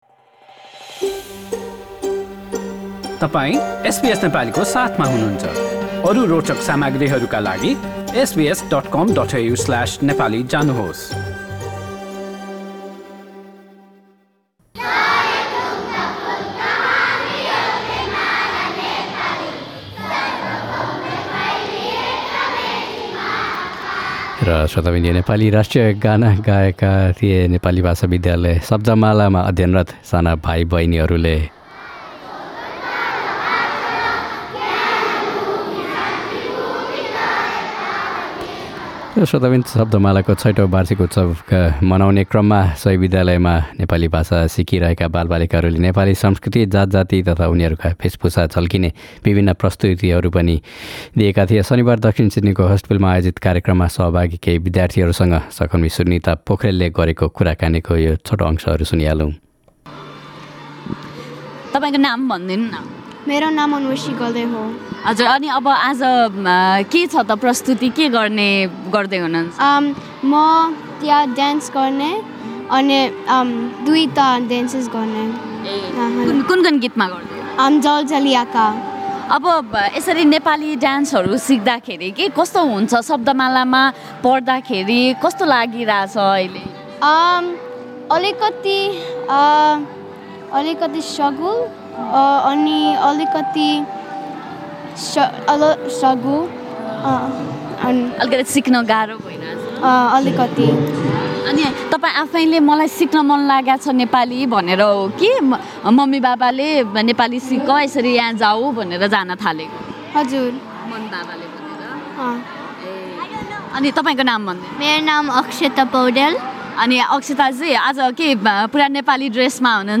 नेपाली परम्परागत भेषभुषामा चिटिक्क परेका बालबालिकाहरूका साथ कार्यक्रममा उपस्थित अभिभावक तथा अतिथिहरूसँग गरिएको कुराकानी सुन्न माथिको मिडिया प्लेयरमा प्ले बटन थिच्नुहोस्।